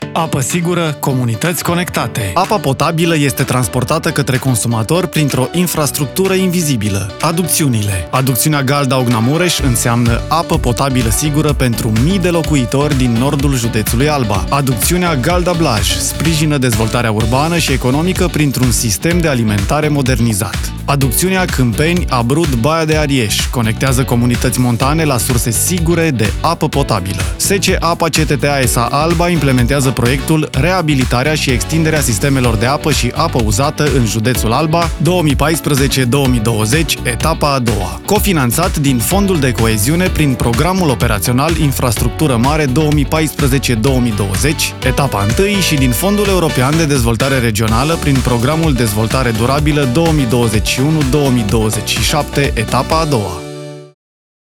Spot publicitar